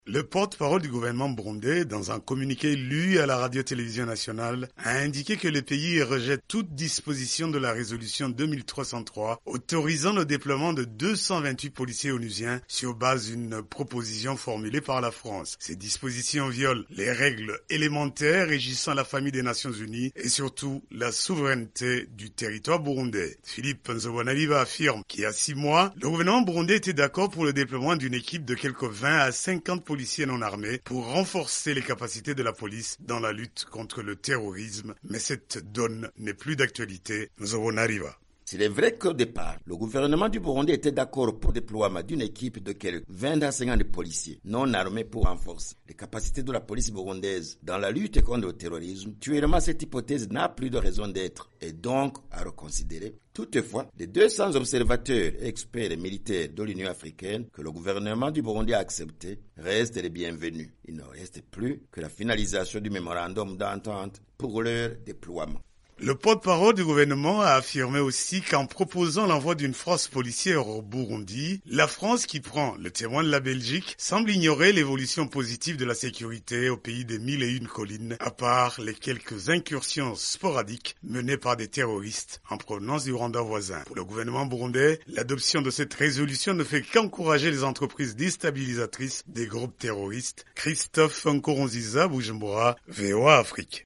Brèves Sonores